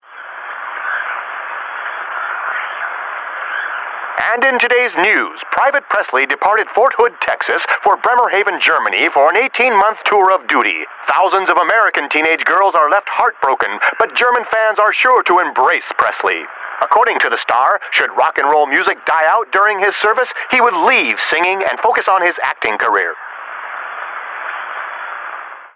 描述：在上午的无线电波段设置1400时，夜间的无线电静音。
Tag: 收音机 振幅调制 静态 频率 幅度调制 噪声